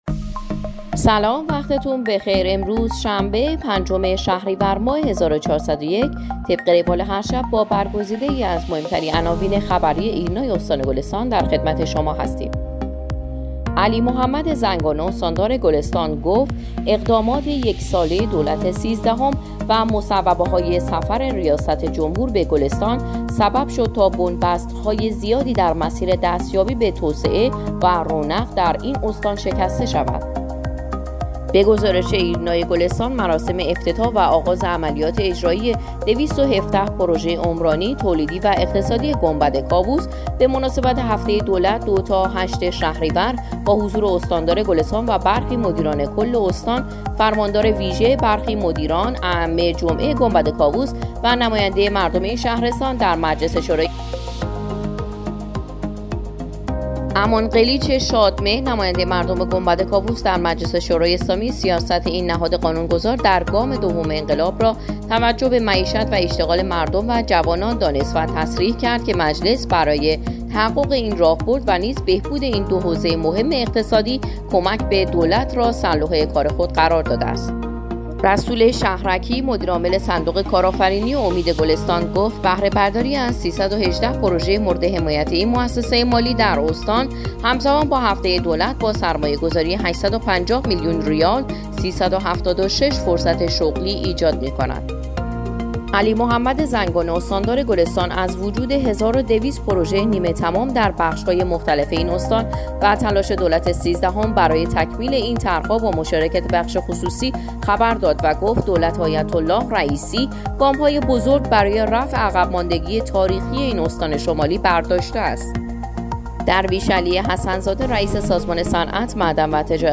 صوت| اخبار شبانگاهی ۵ شهریورماه ایرنا گلستان